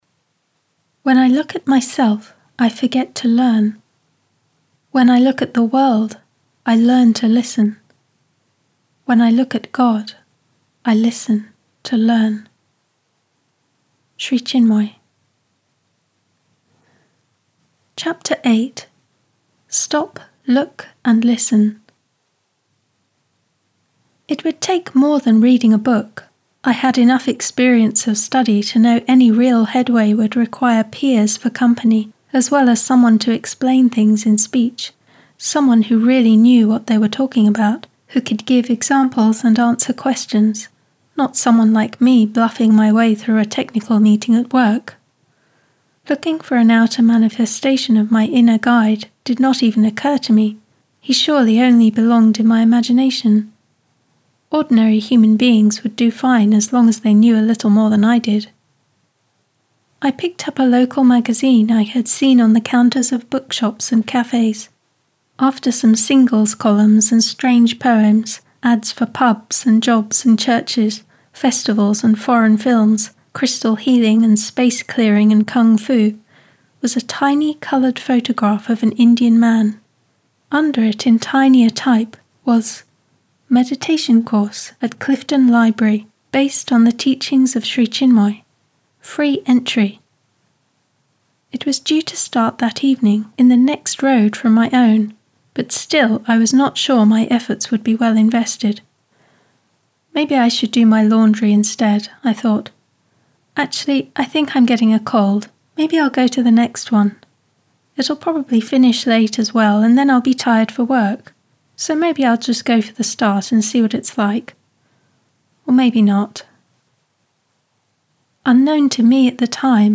Audiobooks | Radio Sri Chinmoy
Another book that is most dear to Sri Chinmoy’s students; these poems detail a series of sweet conversations, in which you can feel the deep closeness between man and God. In this audio track, Sri Chinmoy reads a selection of poems from the book.